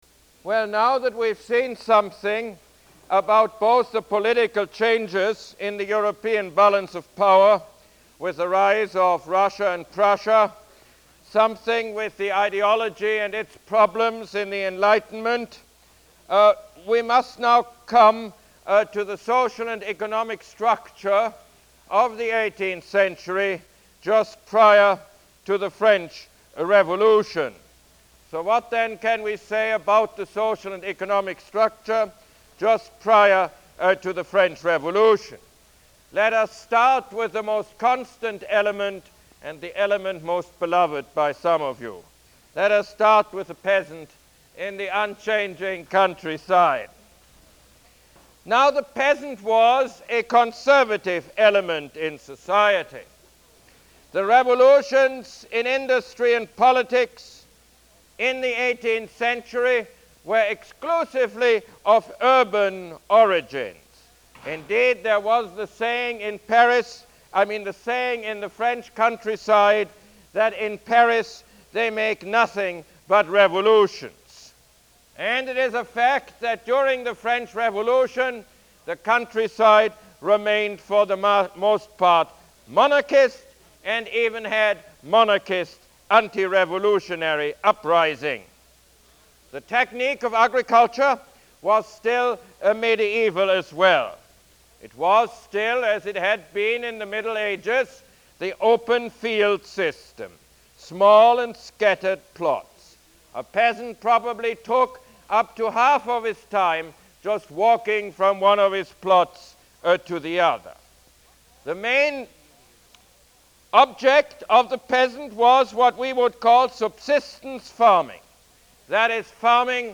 Lecture #23 - The Social Science